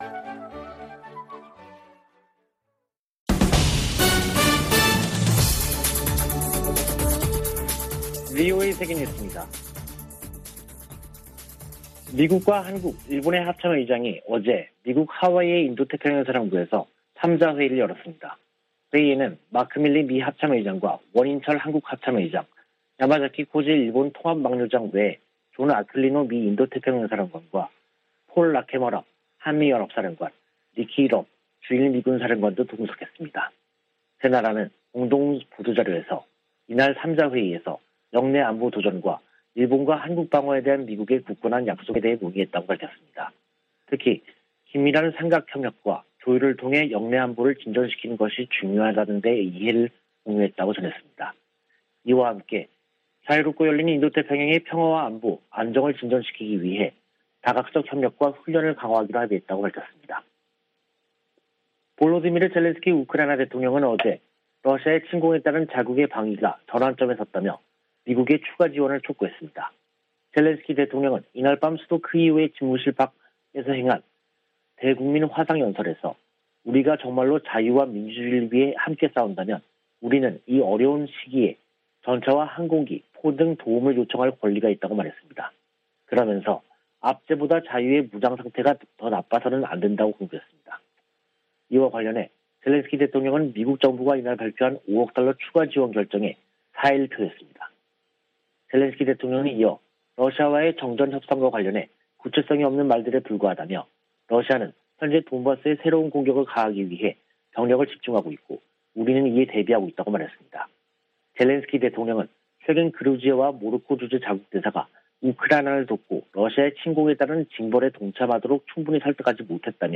VOA 한국어 간판 뉴스 프로그램 '뉴스 투데이', 2022년 3월 31일 3부 방송입니다. 북한이 최근 ICBM을 발사한 곳이 평양 순안공항 인근 미사일 기지에서 멀지 않은 곳으로 확인됐습니다. 북한이 최근 발사한 ICBM이 화성 17형이 아닌 화성 15형이라고 한국 국방부가 밝힌 가운데 미 당국은 여전히 분석 중이라는 입장을 내놨습니다. 북한에서 6개월 안에 식량상황 악화 등 인도주의적 위기가 발생할 가능성이 있다고 스위스 비정부기구가 전망했습니다.